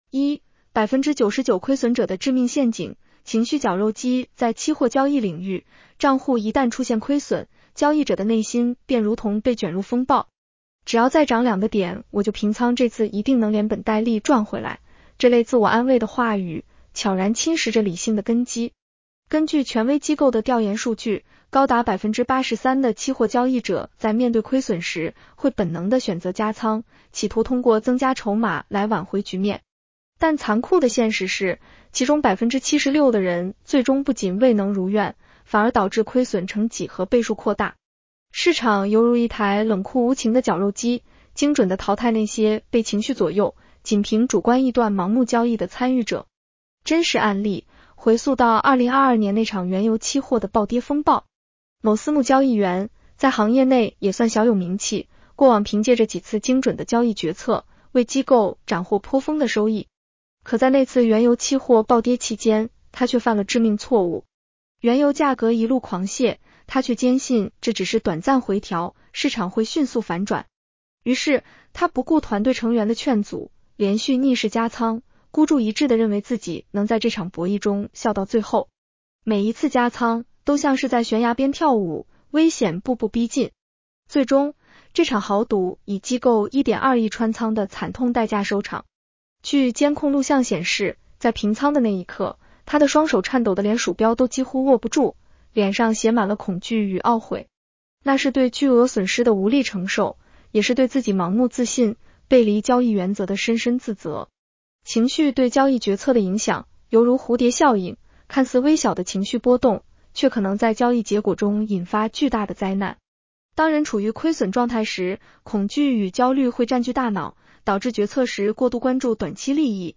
女声普通话版 下载mp3 一、99% 亏损者的致命陷阱：情绪绞肉机 在期货交易领域，账户一旦出现亏损，交易者的内心便如同被卷入风暴。“